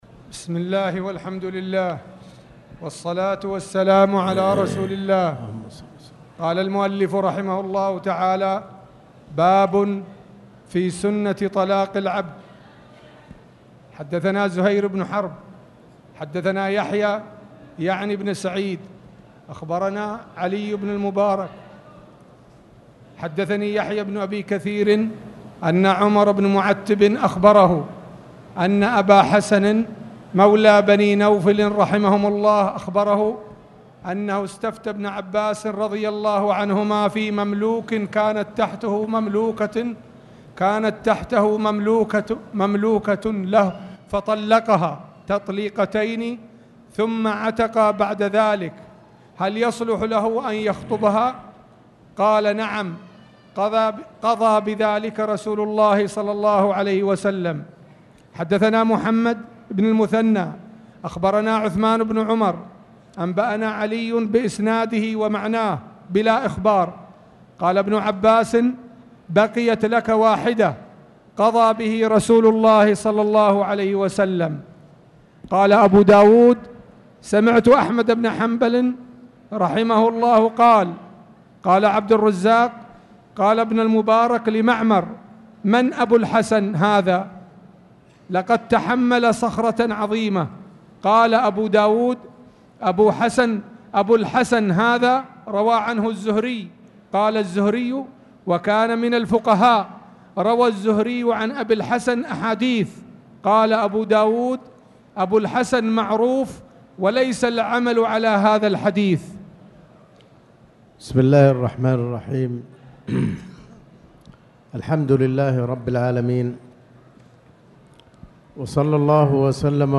تاريخ النشر ٢٥ ربيع الأول ١٤٣٨ هـ المكان: المسجد الحرام الشيخ